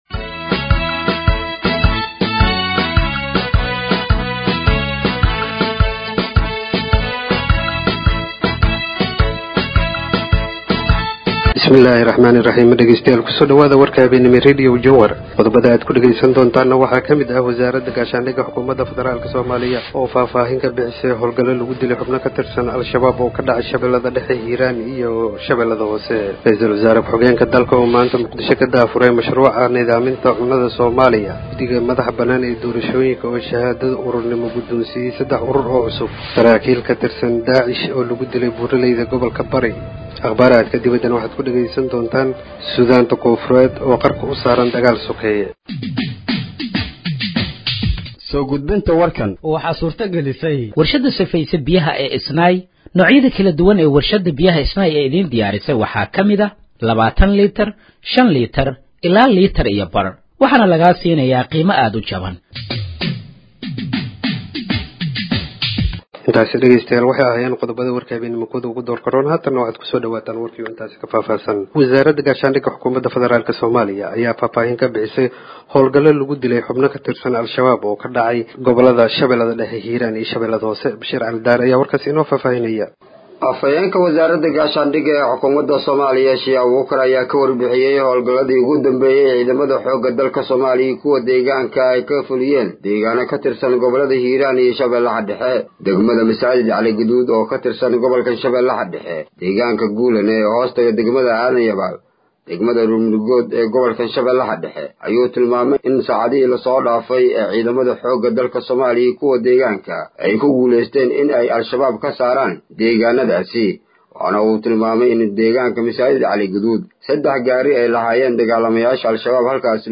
Dhageeyso Warka Habeenimo ee Radiojowhar 08/04/2025